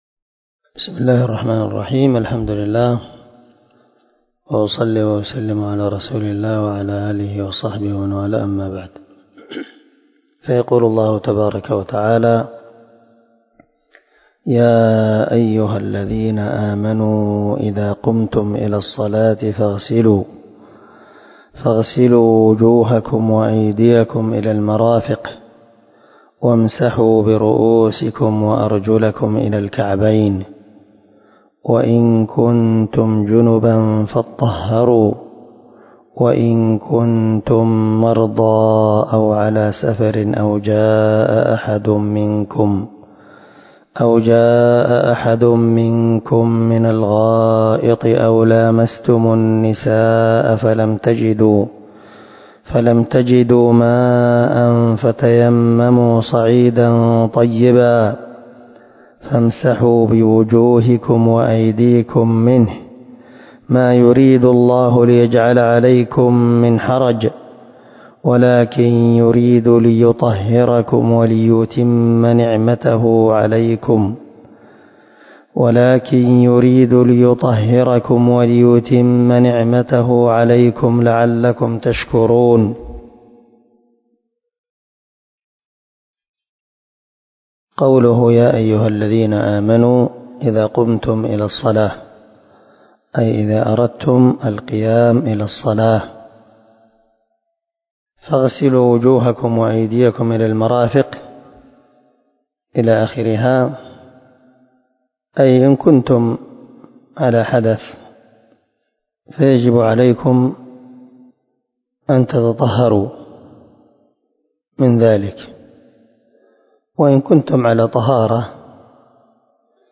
341الدرس 8 تفسير آية ( 6 ) من سورة المائدة من تفسير القران الكريم مع قراءة لتفسير السعدي
دار الحديث- المَحاوِلة- الصبيحة.